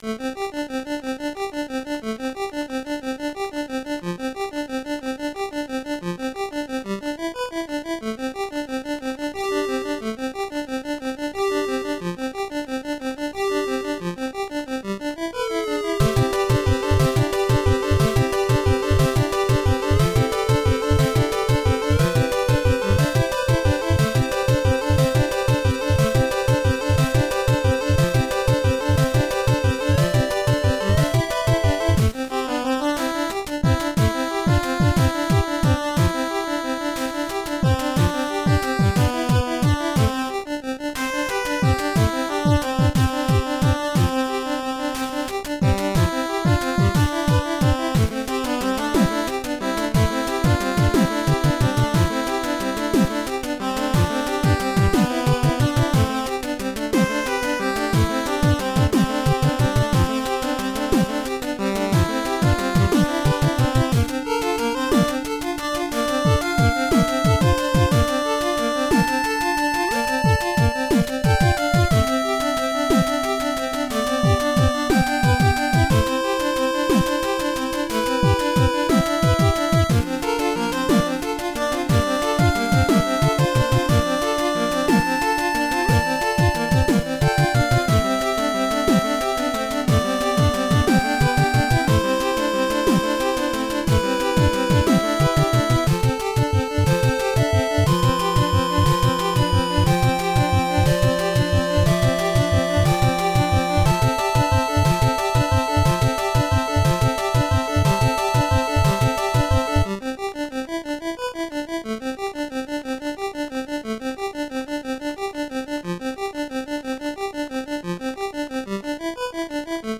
chiptune chip tune 8-bit 8bit 8 bit